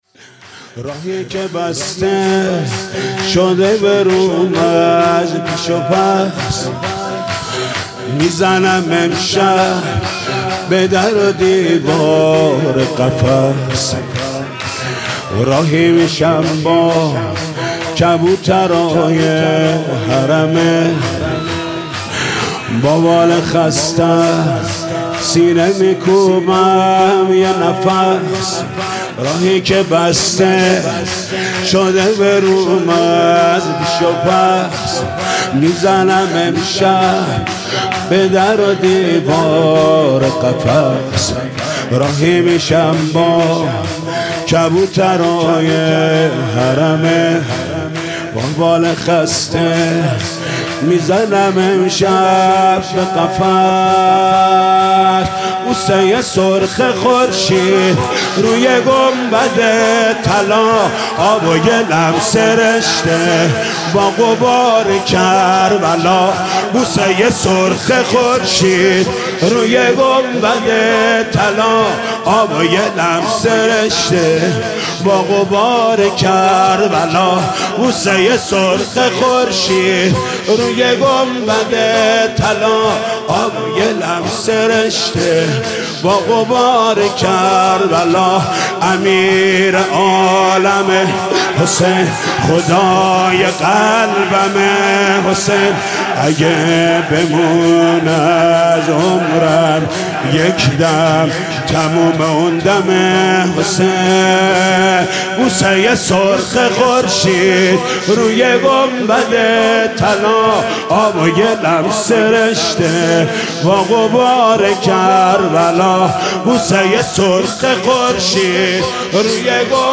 «شب نوزدهم» شور: بوسه ی سرخ خورشید روی گنبد طلا